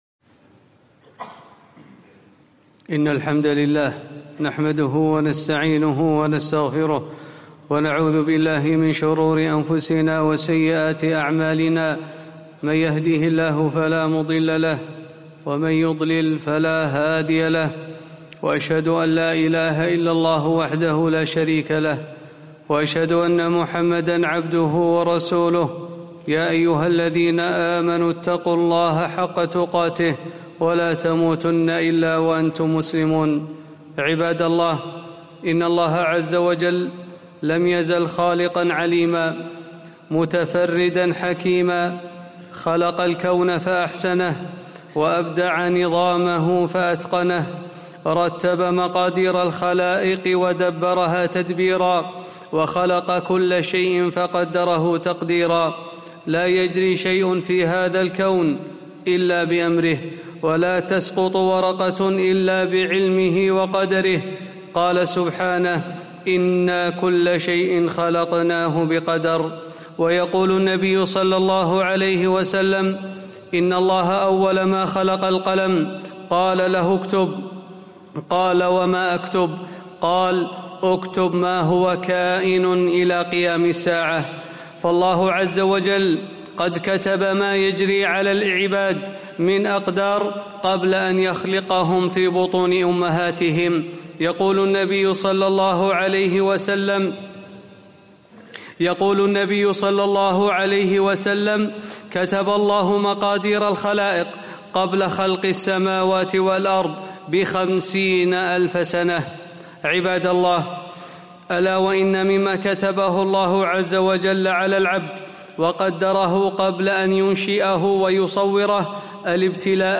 خطبة - كثرة البلاء والصبر عليه